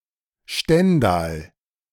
The Hanseatic City of Stendal (German pronunciation: [ˈʃtɛndaːl]